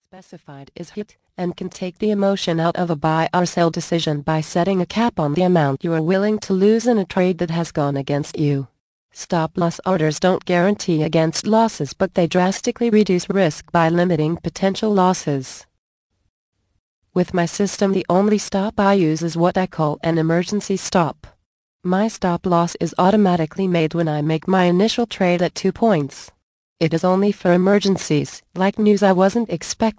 Thanks for your interest in this audio book.